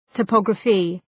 Προφορά
{tə’pɒgrəfı} (Ουσιαστικό) ● τοπογραφία